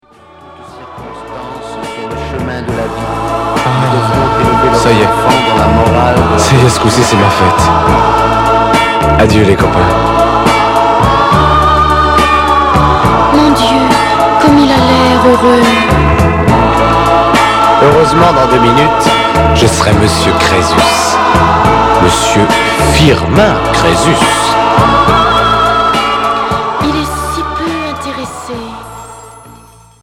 Pop psychédélique Unique 45t